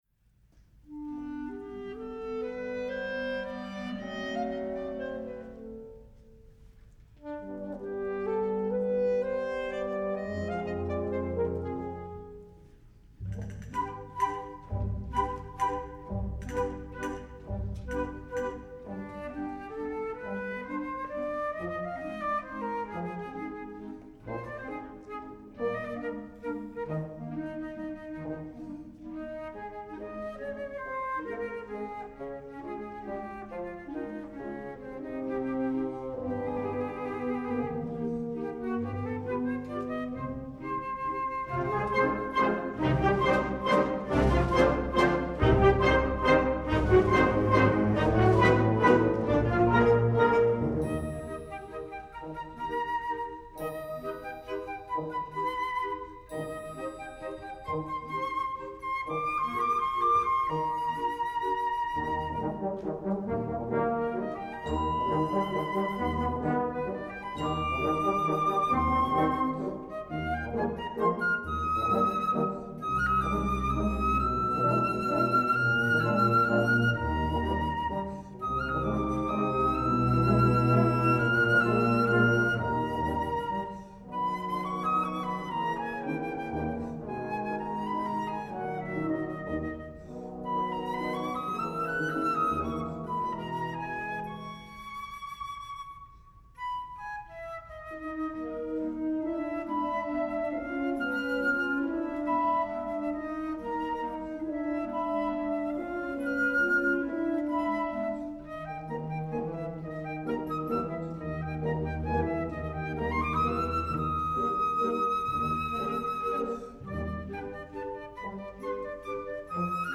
Voicing: Flute Solo w/ Band